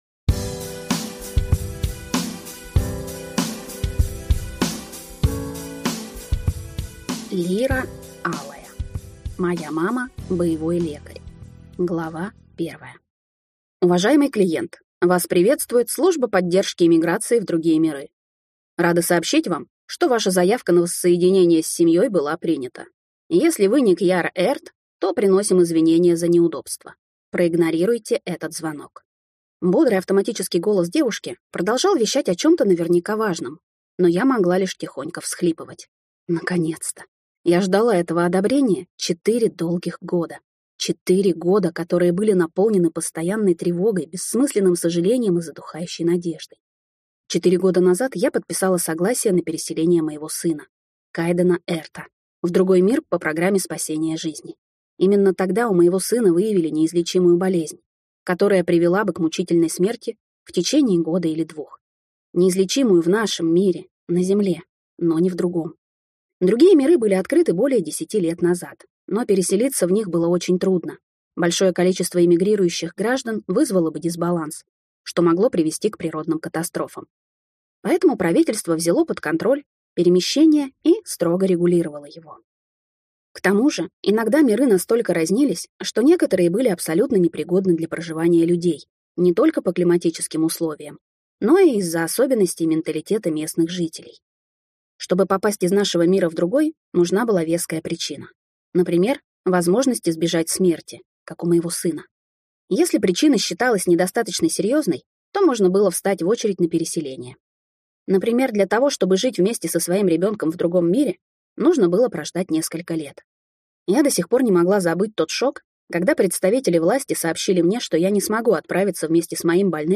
Аудиокнига Моя мама – боевой лекарь | Библиотека аудиокниг
Прослушать и бесплатно скачать фрагмент аудиокниги